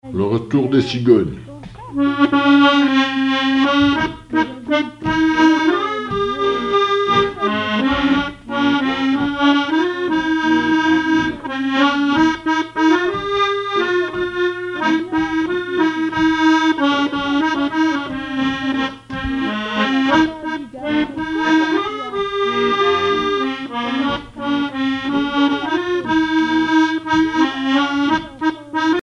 Mémoires et Patrimoines vivants - RaddO est une base de données d'archives iconographiques et sonores.
accordéon(s), accordéoniste
danse : valse musette
Pièce musicale inédite